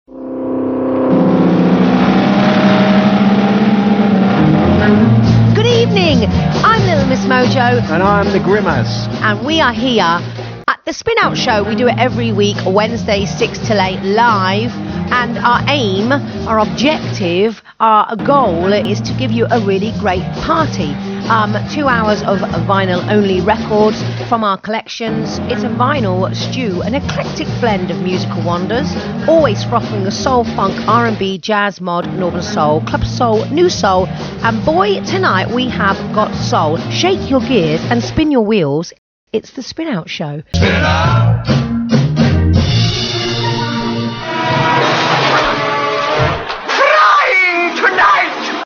Spinout Show ad.
Tags: NCB Show Ads